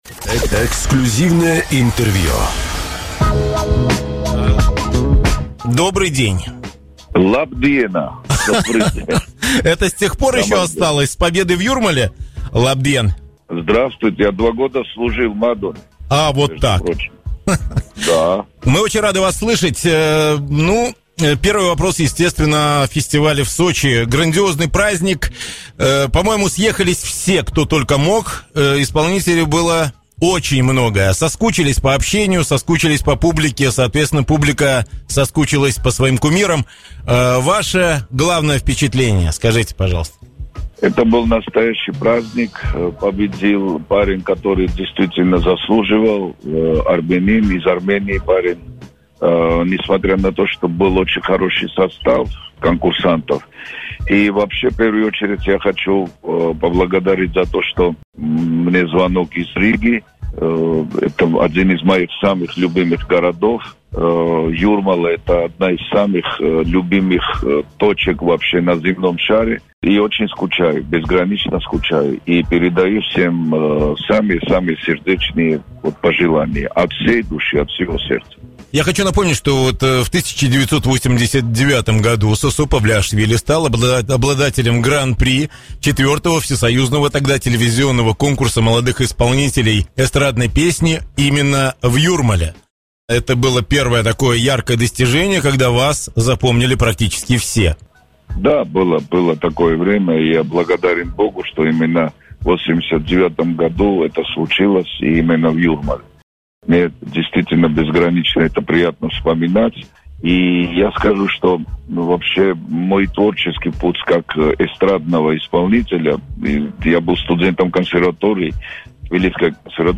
Эксклюзивное интервью с Сосо Павлиашвили